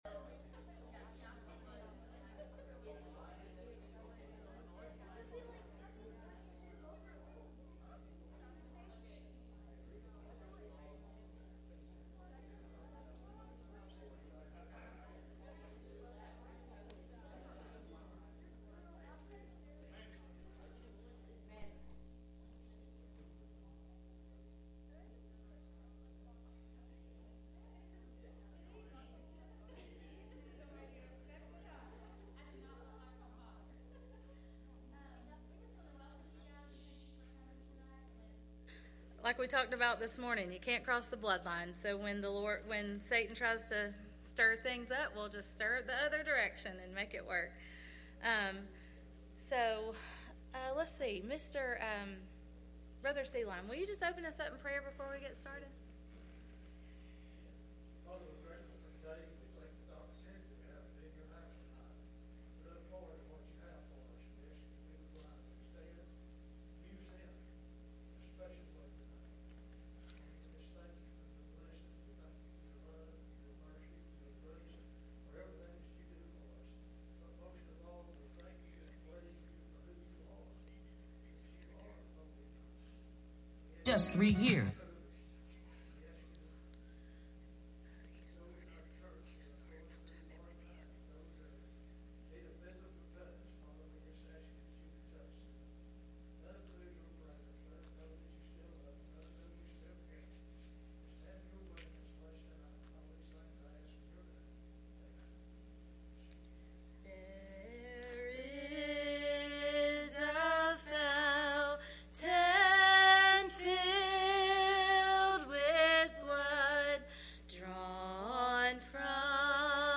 Sunday Night Service
Service Type: Sunday Evening